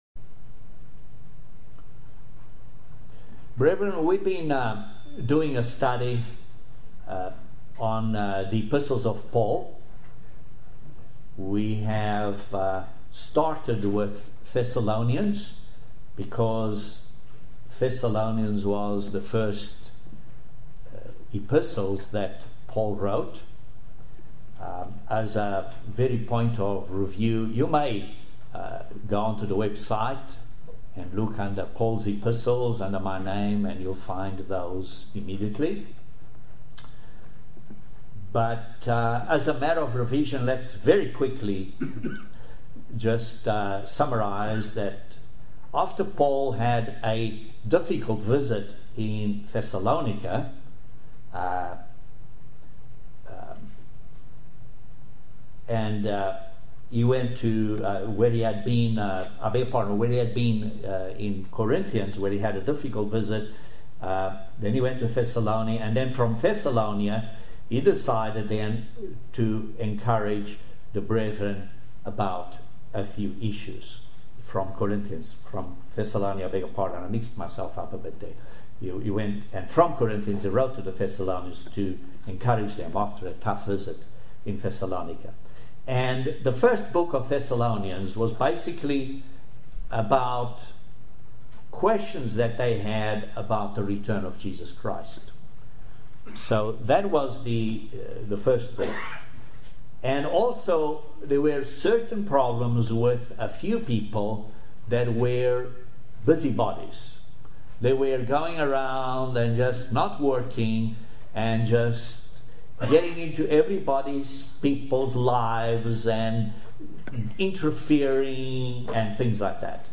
Join us for this amazing video sermon and study of Paul's Epistles. This segment is on the study of II Thessalonians 3 with an introduction to Galatians.